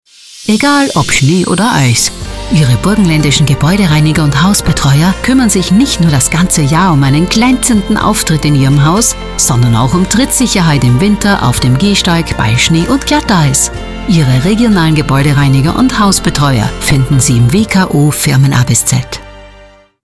Radiospots der Landesinnung
radiospot-gebaeudereiniger-schnee.mp3